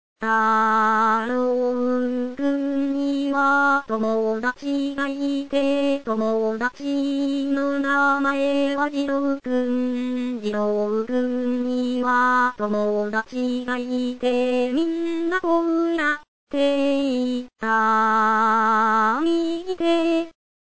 段階Ｃまねる分類アクションソング
アカペラを聴く